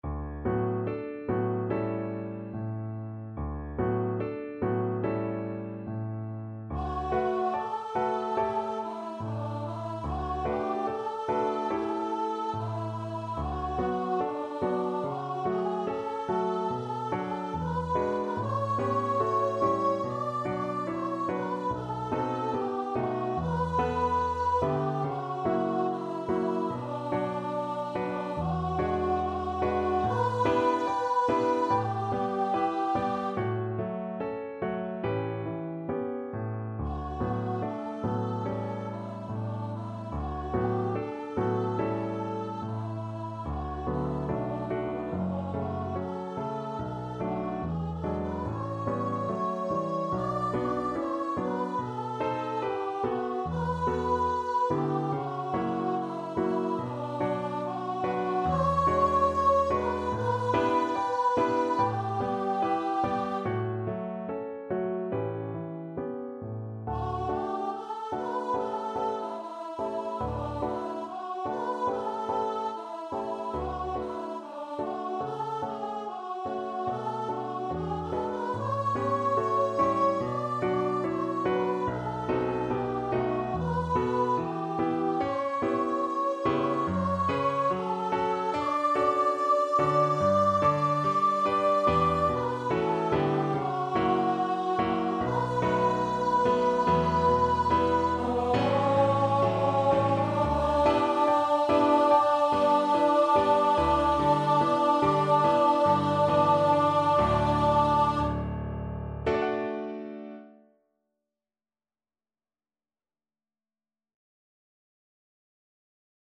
4/4 (View more 4/4 Music)
~ = 72 In moderate time
Classical (View more Classical Voice Music)